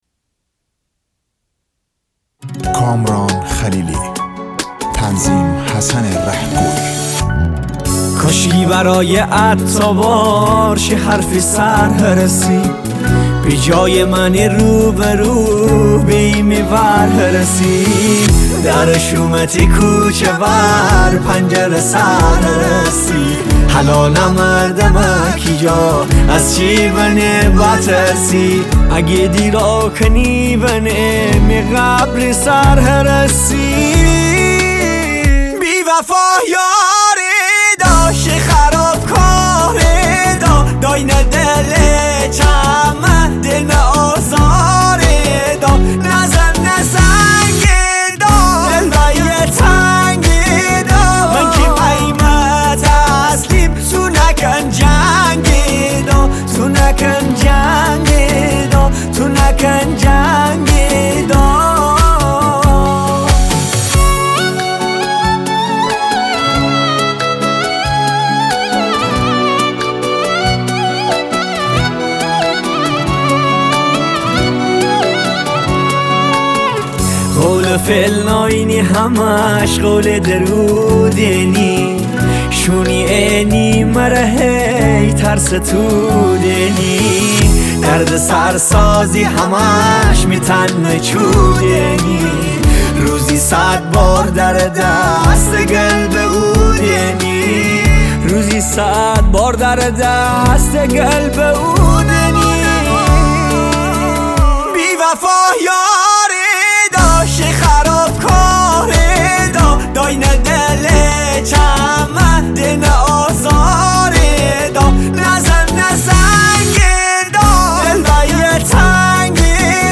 آهنگ جدید عاشقانه و احساسی